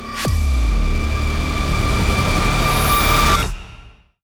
reel_anticipation2.wav